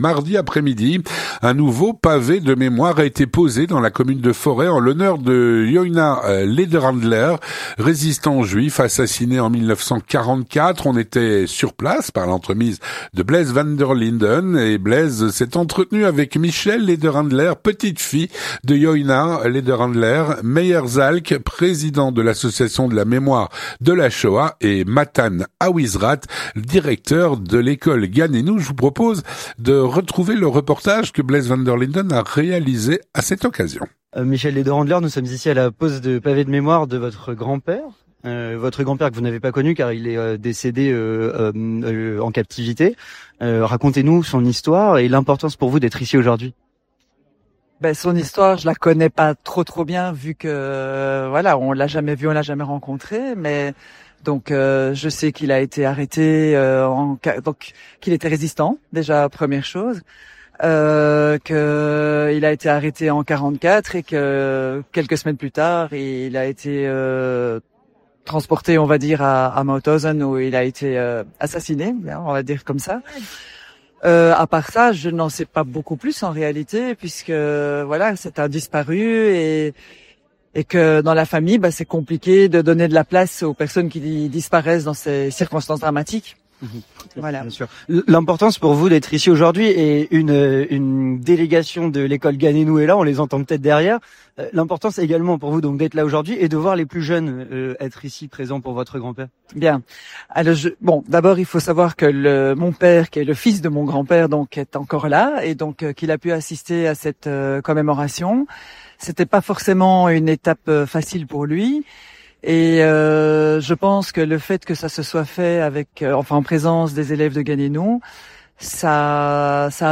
3. Témoignage